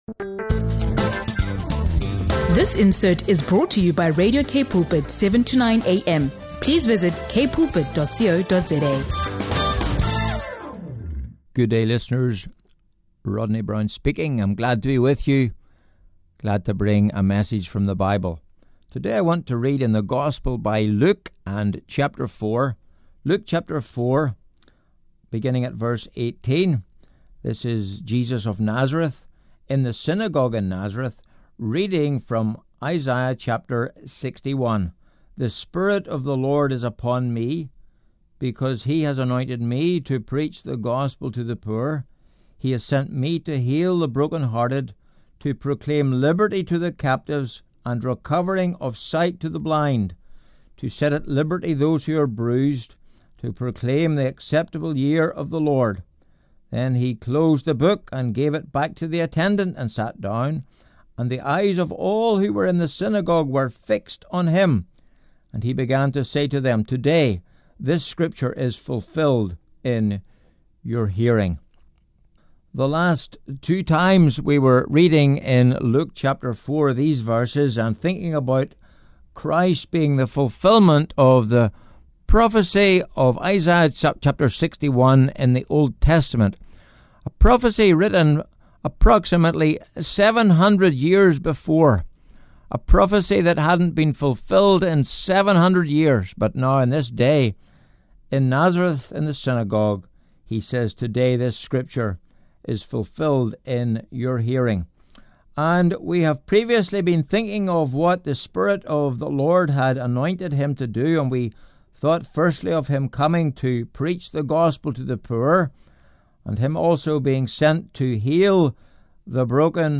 SERMONS & PREKE